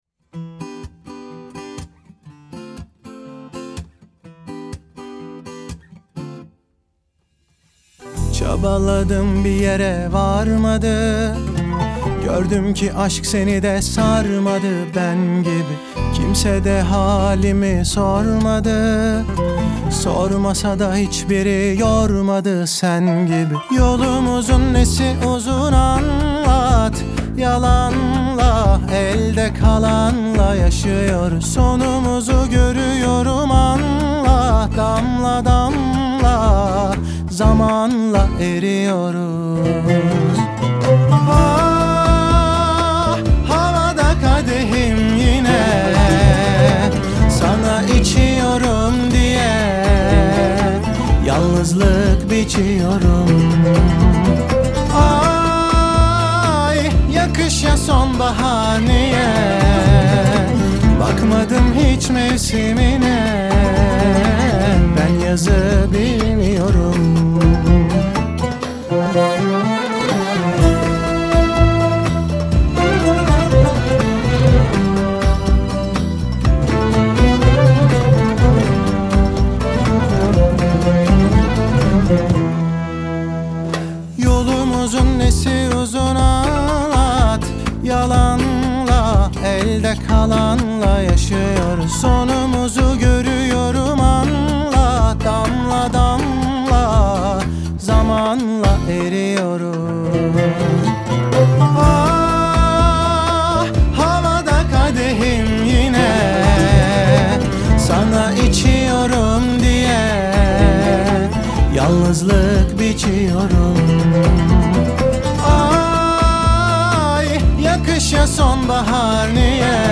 Трек размещён в разделе Турецкая музыка / Поп / 2022.